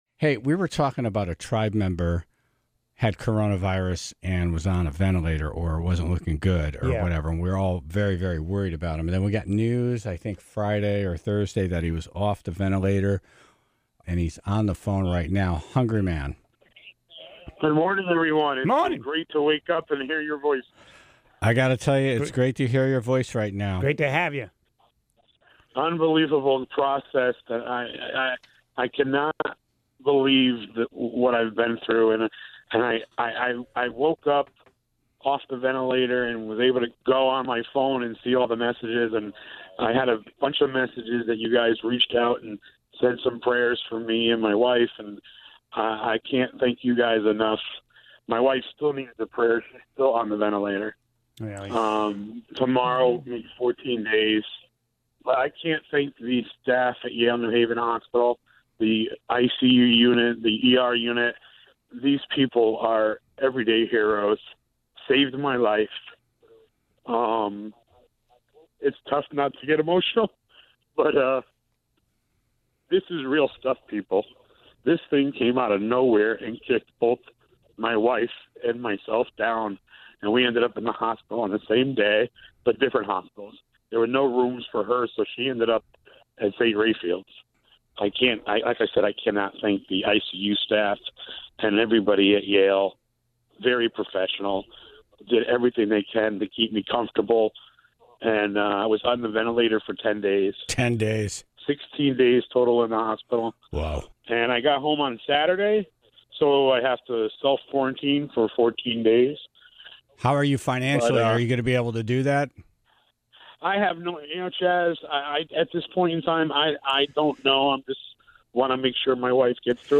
(18:51) Senator Bluementhal calls in to answers the Tribe's questions about their stimulus checks, when the money will be available, and what their eligible for. (33:43) What is the difference between the Spanish Flu outbreak, and the COVID-19 pandemic?